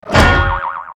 TL_train_cog.ogg